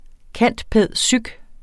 Udtale [ ˈkanˀdˈpεˀðˈsyg ]